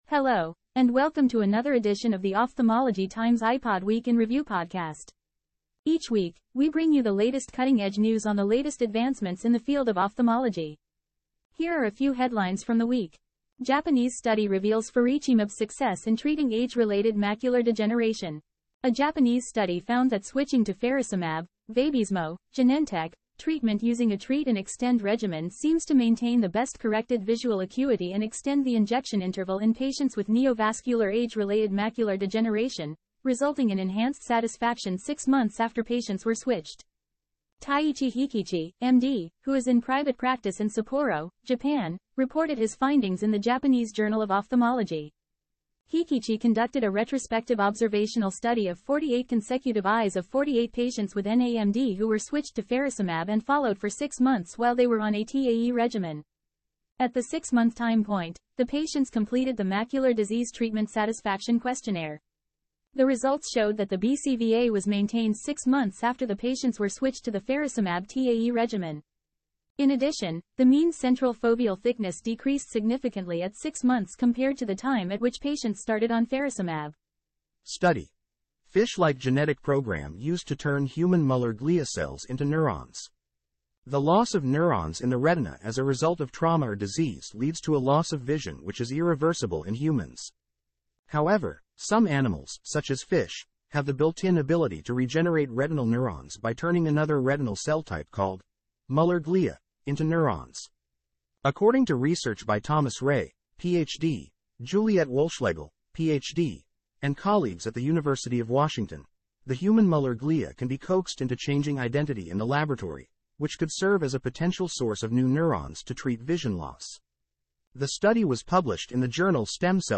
Editor's Note: This podcast was generated from Ophthalmology Times content using an AI platform.